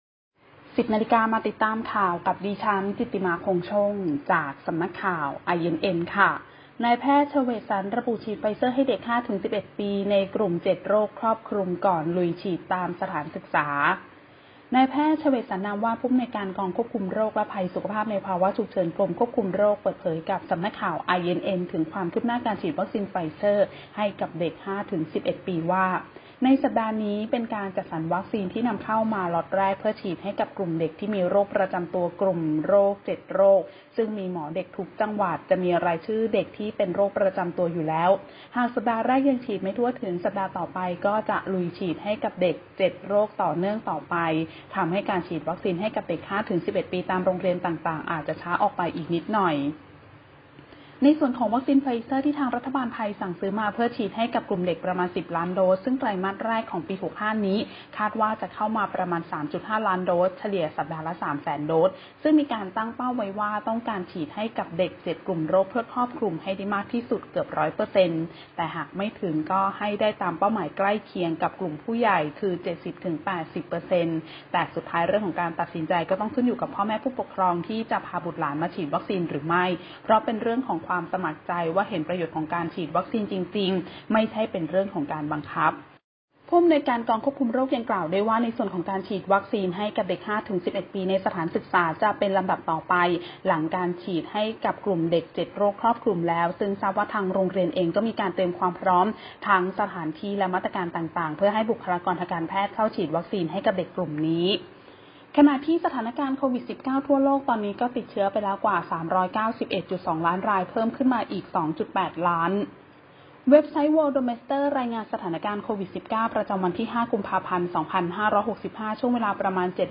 ข่าวต้นชั่วโมง 10.00 น.